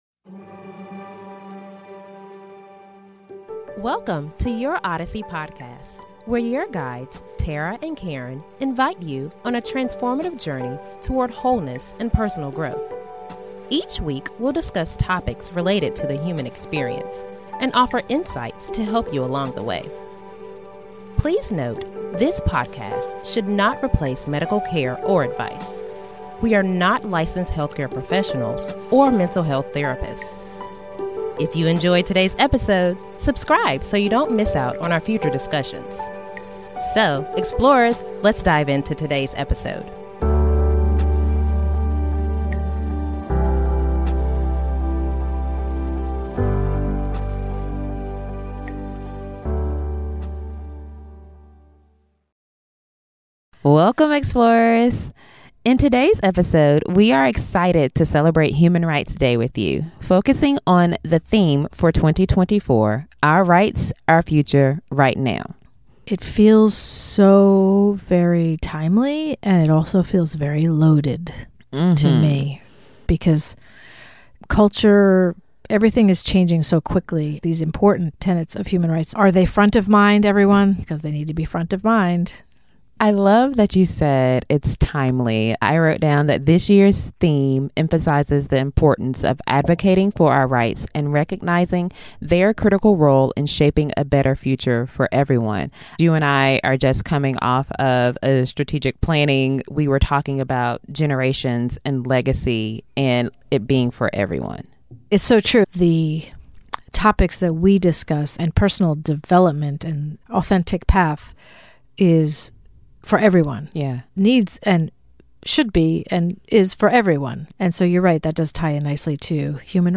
Tune in for a heartfelt conversation on advocating for change and the power of more love in our communities.